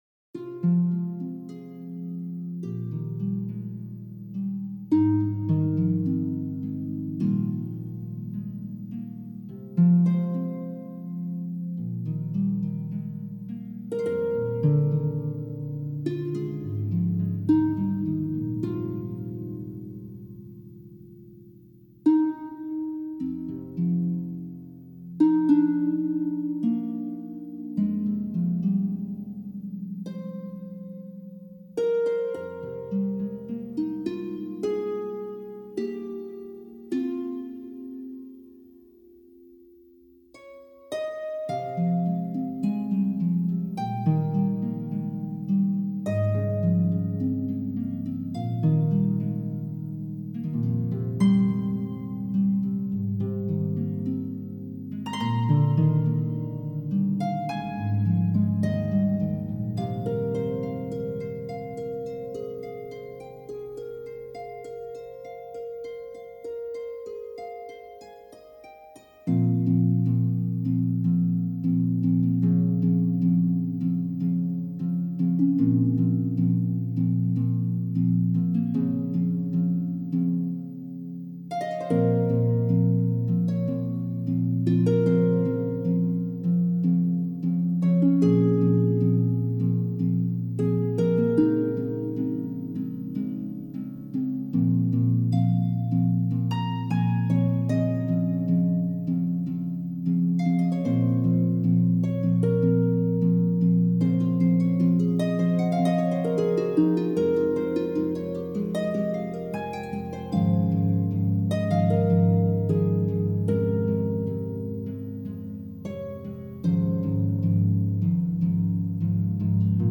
Classical elegance with modern flair